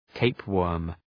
{‘teıp,wɜ:rm}